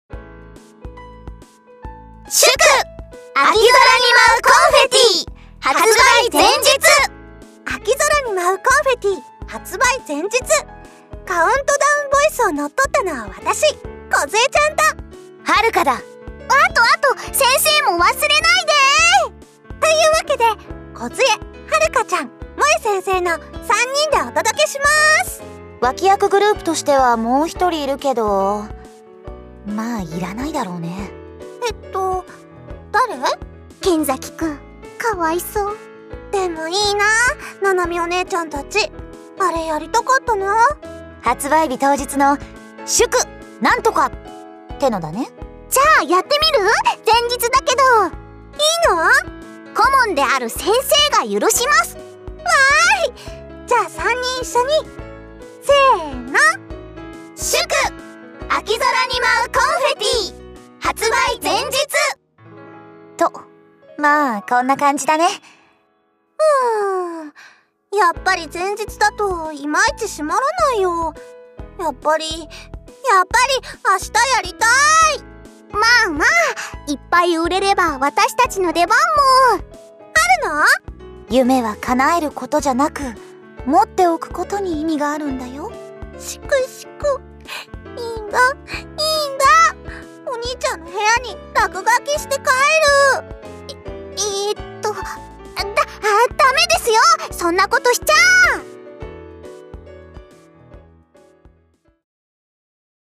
カウントダウンボイス（01日前）を公開しました。
countdown_voice_01.mp3